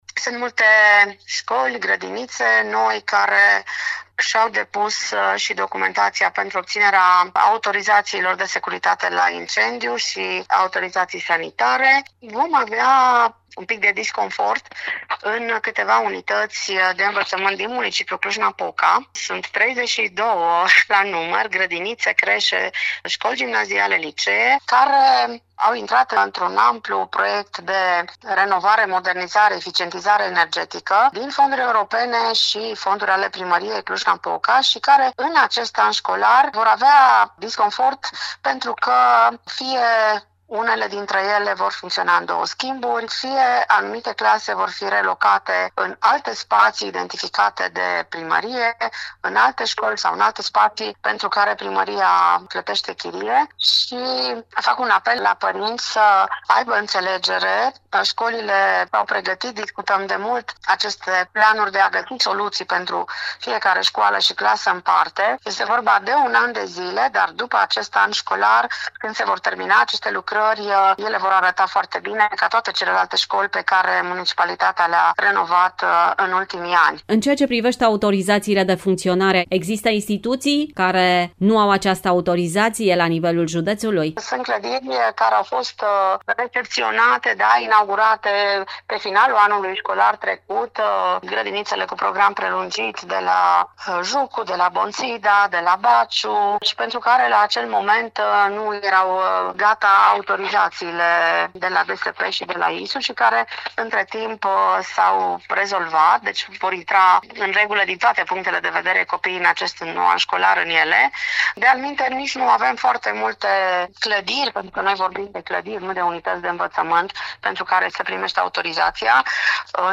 Care este situația instituțiilor de învățământ la nivelul județului ne spune inspectorul școlar general al Inspectoratului Școlar Județean Cluj, Marinela Marc: